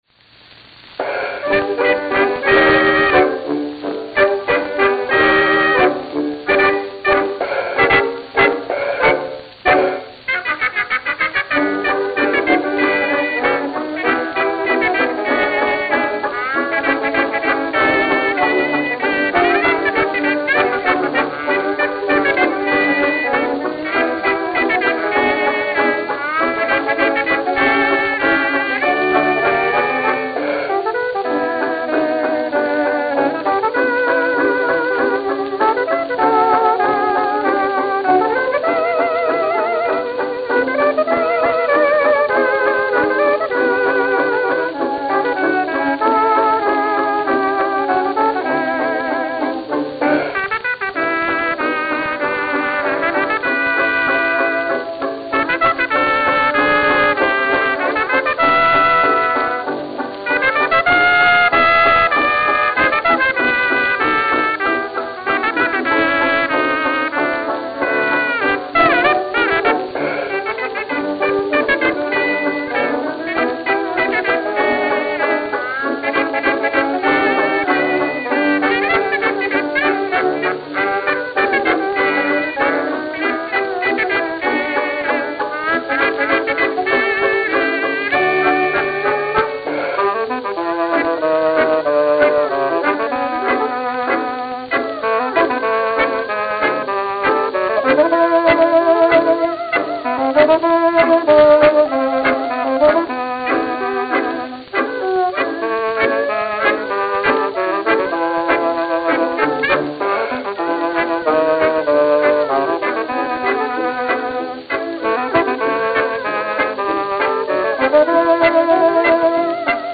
Long Island City, New York Long Island City, New York